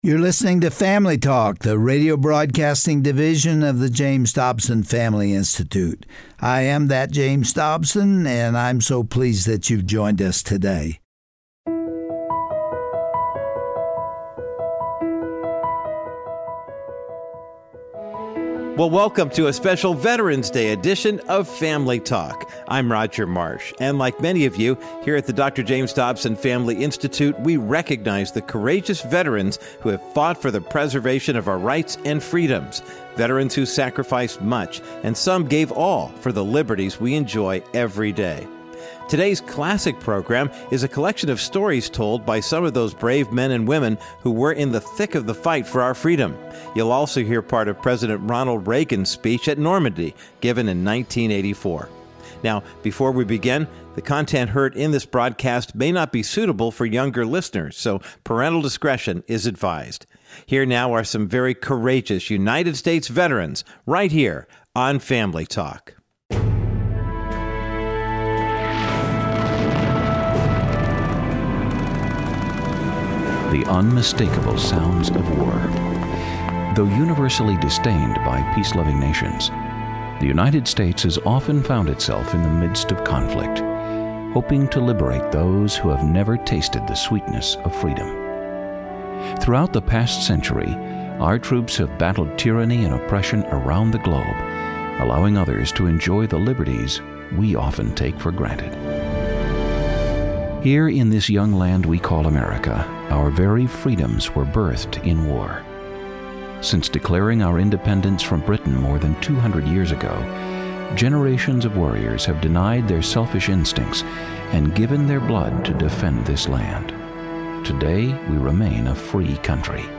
On this special edition of Family Talk, you will hear a sobering radio drama of stories and first-hand accounts of soldiers who fought bravely for our nation’s freedom.
Host Dr. James Dobson
Guest(s):A panel of U.S. Veterans and President Ronald Reagan